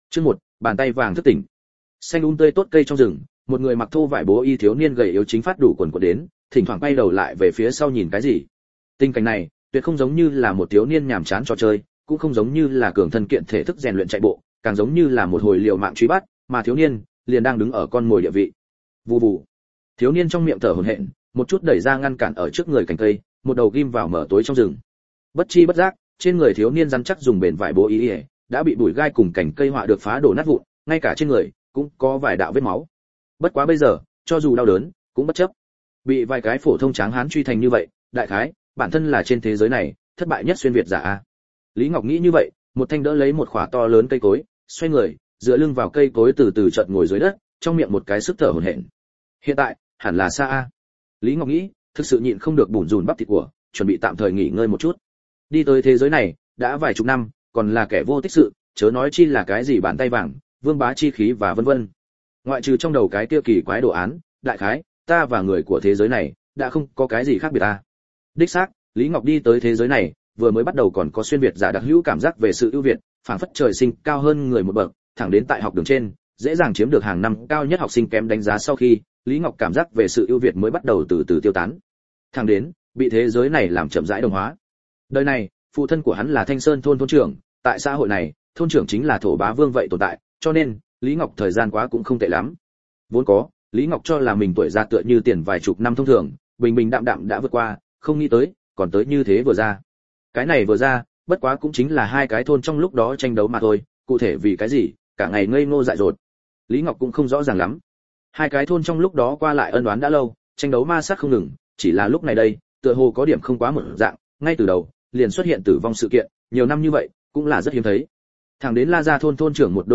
Vị Diện Triệu Hoán Giả Audio - Nghe đọc Truyện Audio Online Hay Trên AUDIO TRUYỆN FULL